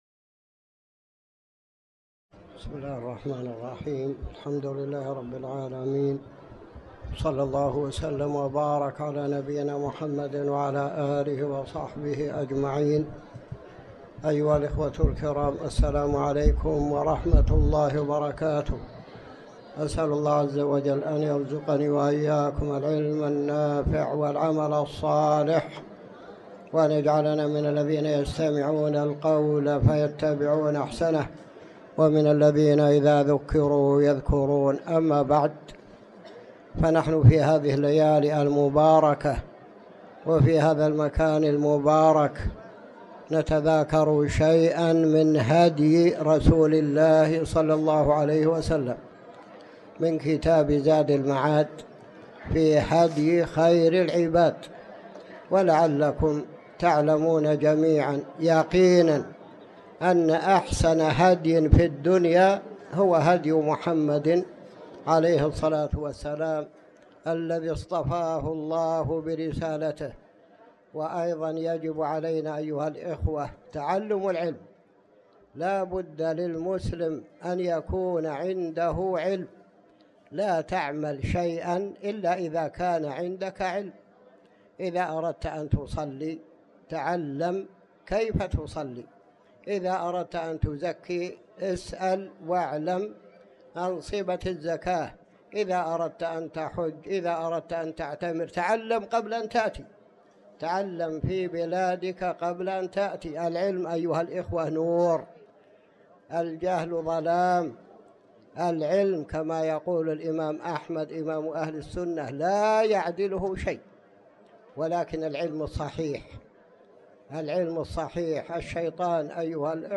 تاريخ النشر ١٧ جمادى الأولى ١٤٤٠ هـ المكان: المسجد الحرام الشيخ